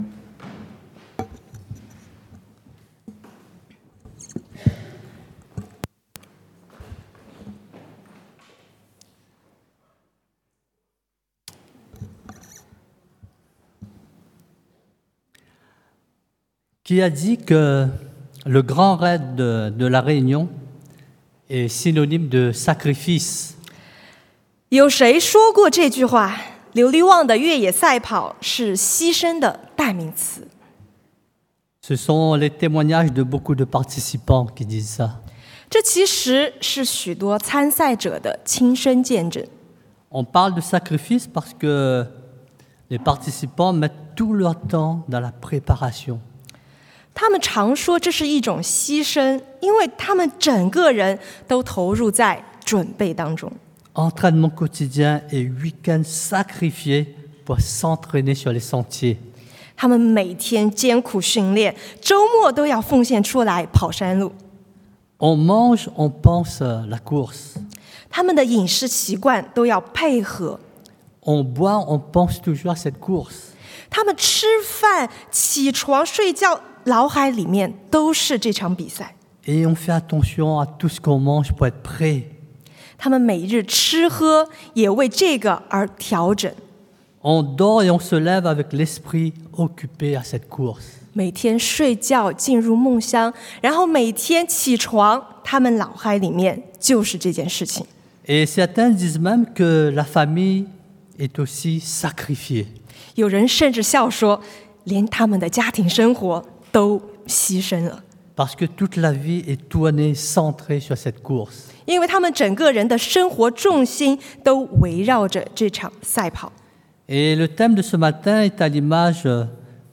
Offrir son corps comme un sacrifice vivant 献上身体作为活祭 – Culte du dimanche